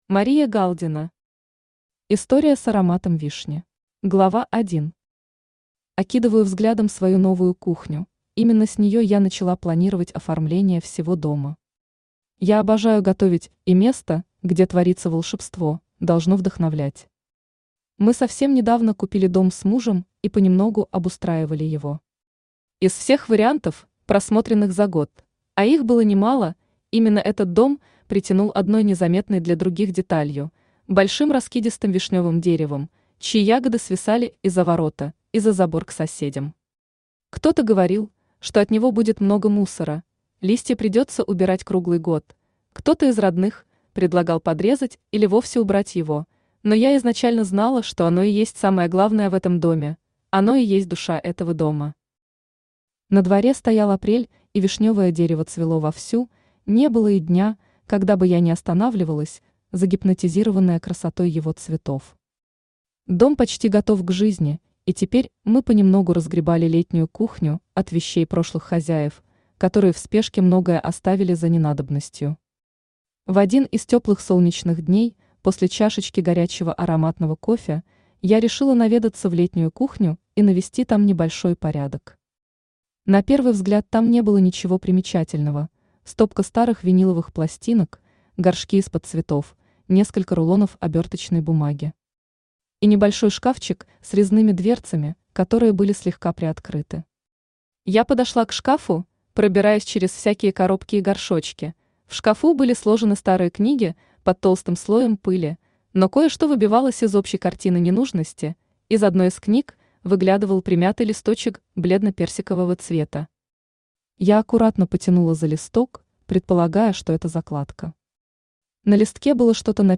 Аудиокнига История с ароматом вишни | Библиотека аудиокниг
Aудиокнига История с ароматом вишни Автор Мария Галдина Читает аудиокнигу Авточтец ЛитРес.